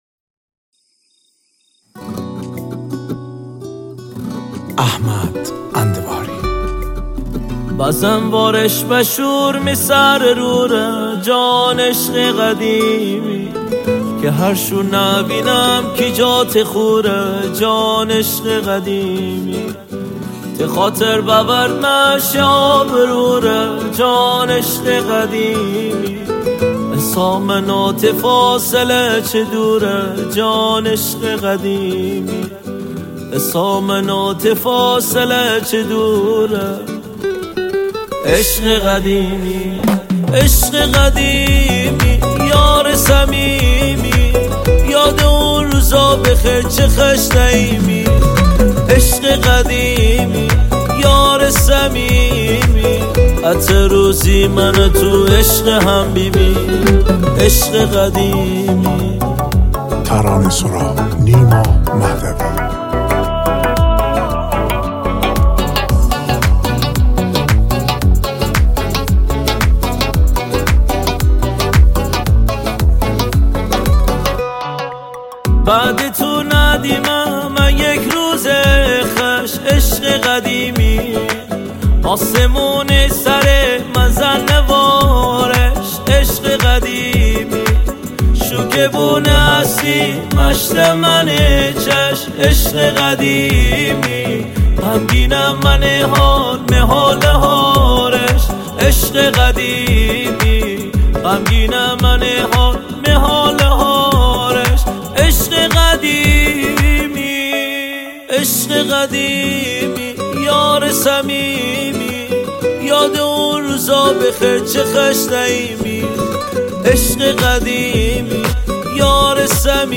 گیتاری